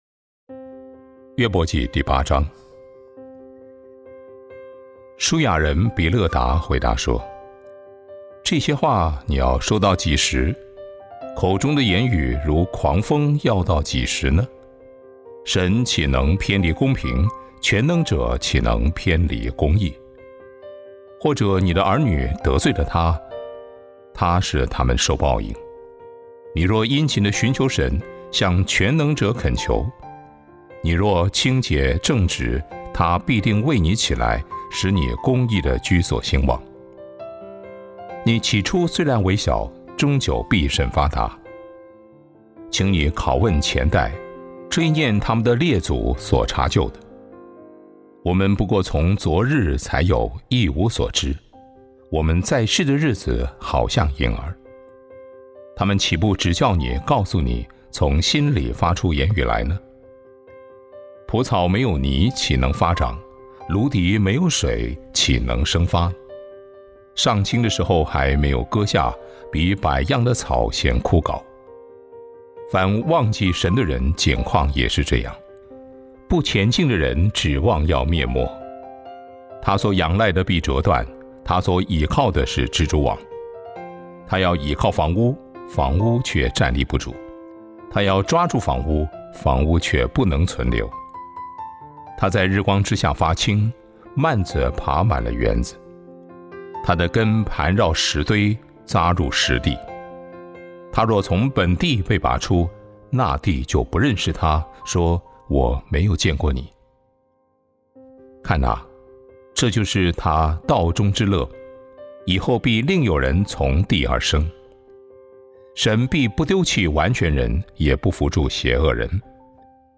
灵修分享